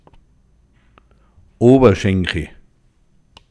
Reith im Alpbachtal